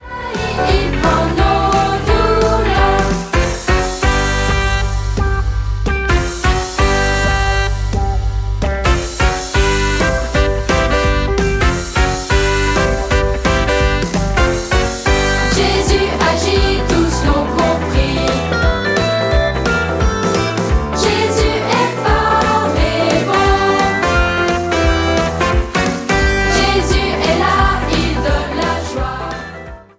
Comédie musicale